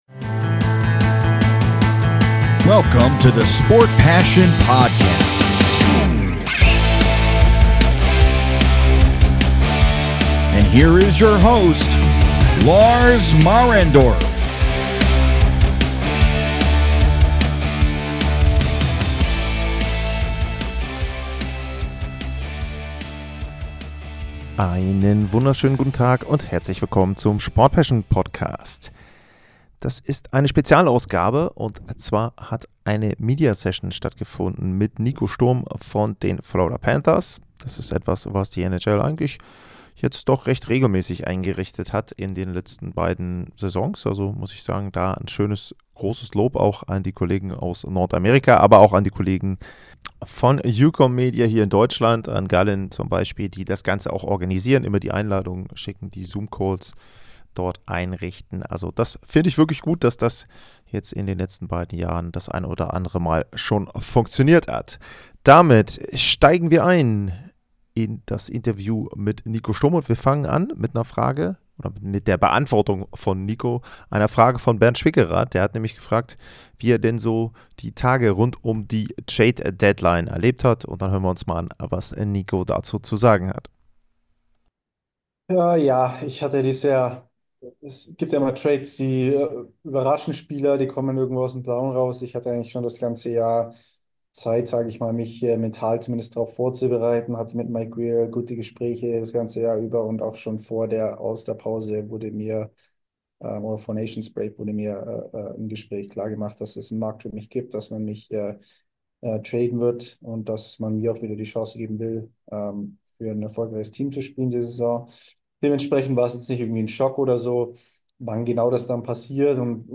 2025-03-28 Nico Sturm stand den deutschen Medienvertretern Rede und Antwort. Der Augsburger berichtet über seinen Wechsel zu den Panthers, Paul Maurice, Brad Marchand und seine Spezialität, die Faceoffs.